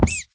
sounds / mob / rabbit / hurt3.ogg
hurt3.ogg